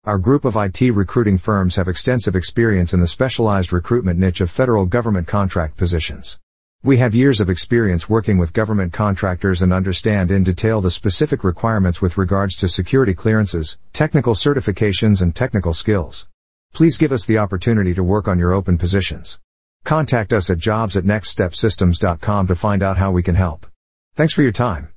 Security Clearance Employers, Listen to Our Artificial Intelligence (AI) on How Our IT Staffing Company Can Help You with Government and Federal Information Technology Openings
Please take a moment to listen to an audio file about our IT staffing company employer services generated by Artificial Intelligence (AI). We have years of experience placing information technology security clearance candidates in federal and government contract IT openings.